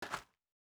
Shoe Step Gravel Medium B.wav